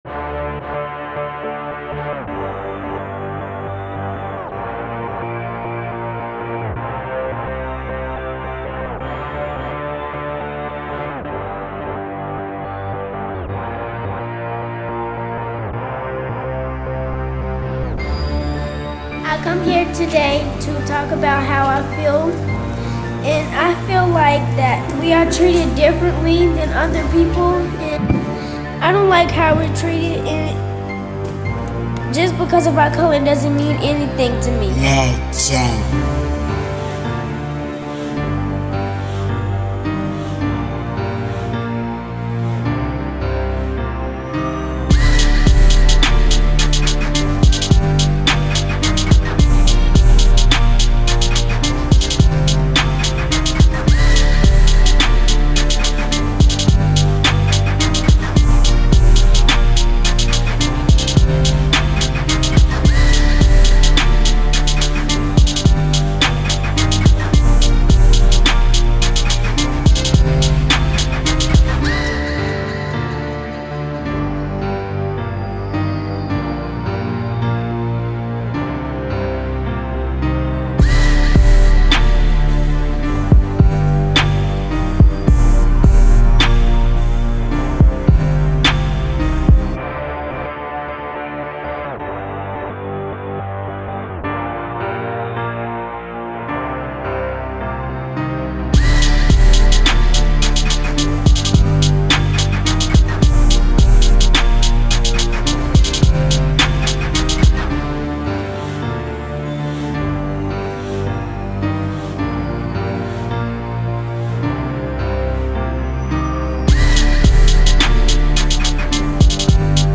Genero: Trap